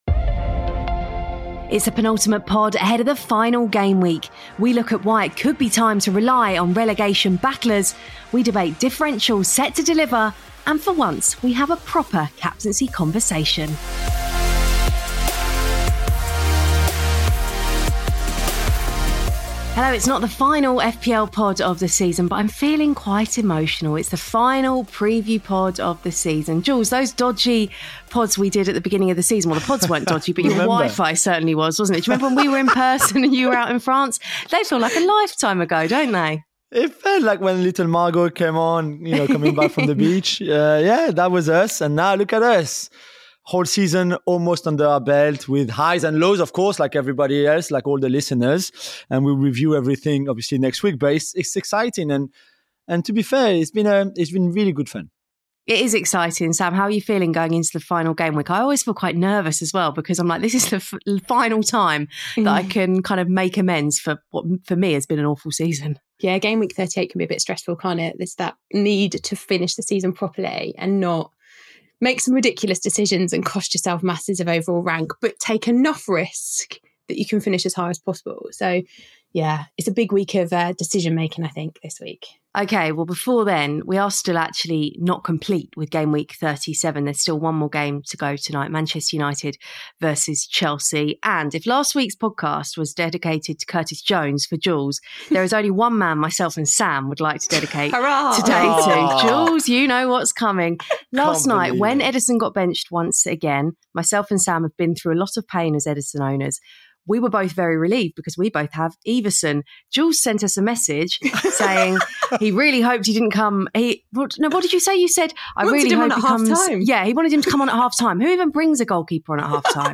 Kelly Somers and the FPL gang are joined ahead of the final Gameweek of the season by former Premier League striker Dean Ashton ⚽